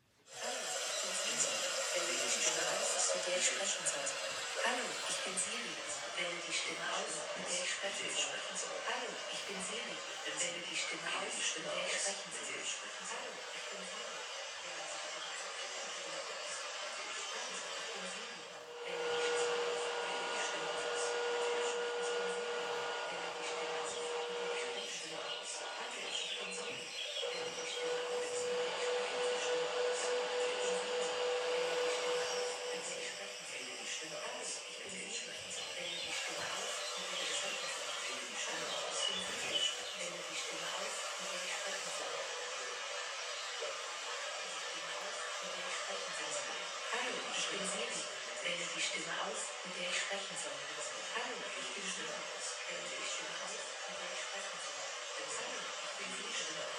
M6_Soundscape als Mp3-Datei für 2. Doppelstunde - Einstieg
mp3-datei-soundscape-sonic-moves-FQx29RgAEs6nZyBDiPyMMv.m4a